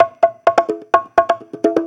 Conga Loop 128 BPM (6).wav